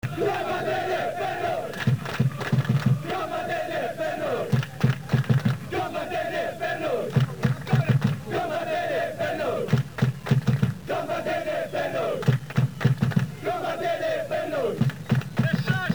I cori in Mp3 degli Ultrà Lodigiani
Purtroppo l'acustica non è perfetta ma questo è quanto riusciamo a fare.
Partite varie in casa